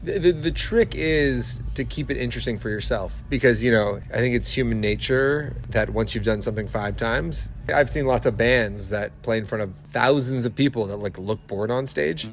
Max Kerman is the lead singer for The Arkells and spoke to Quinte News sidestage about loving live music.